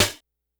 Snares
snr_43.wav